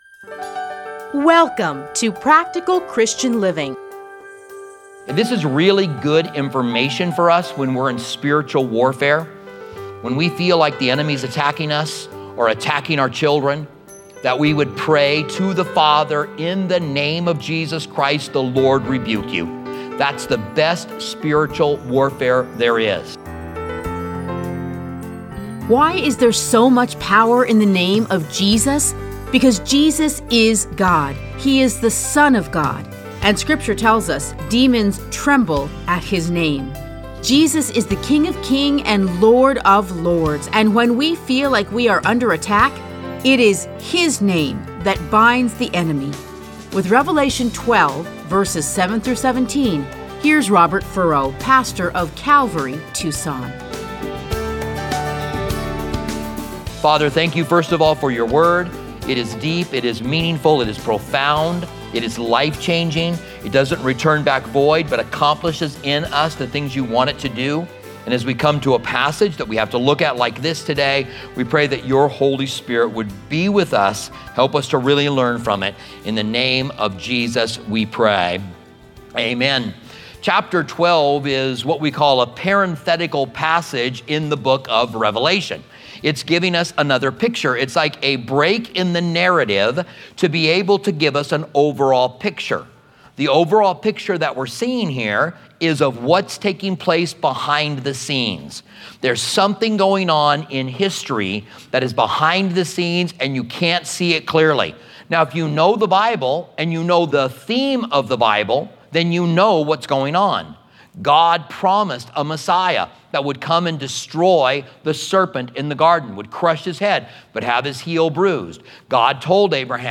edited into 30-minute radio programs titled Practical Christian Living. Listen to a teaching from Revelation 12:7-17.